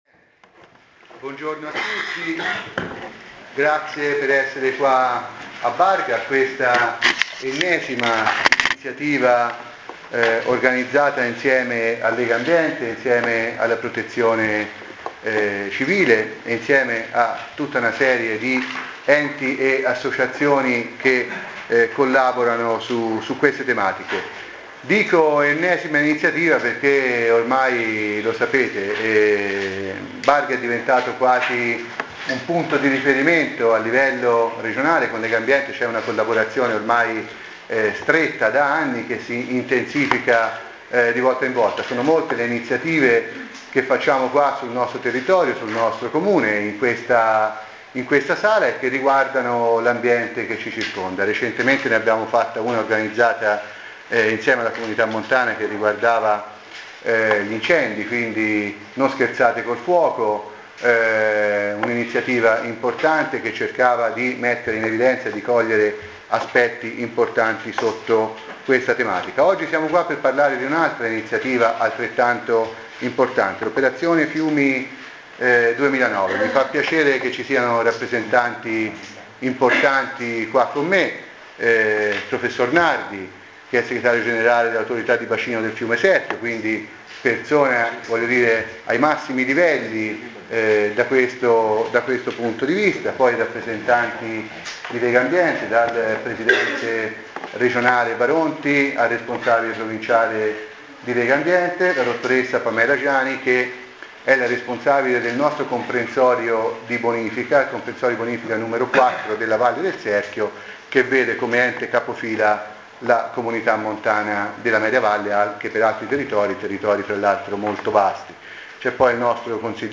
Click on the link below to hear the opening speech by the Mayor of Barga Marco Bonini at today’s conference on “Operazione Fiumi”
bonini_lega_ambienti_barga_17oct2009